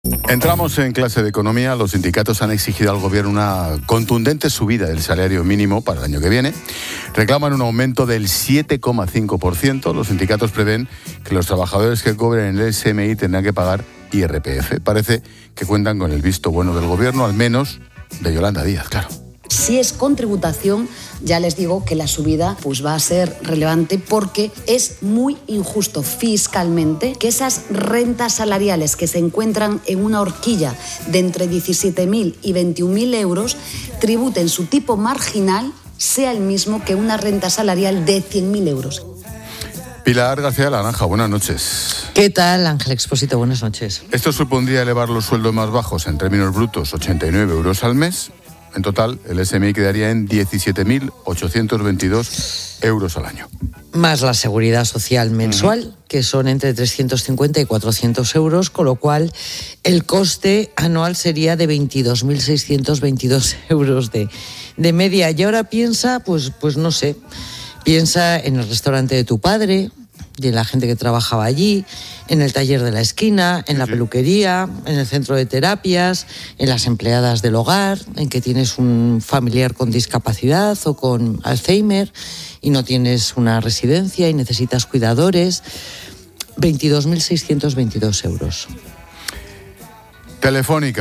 Pilar García de la Granja, experta económica
Expósito analiza en Clases de Economía con la experta económica y directora de Mediodía COPE, Pilar García de la Granja, la subida del salario mínimo que exigen los sindicatos